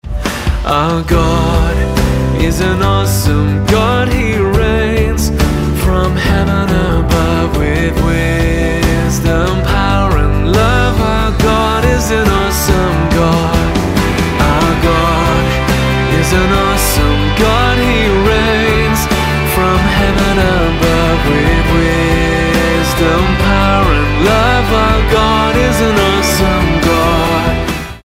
Em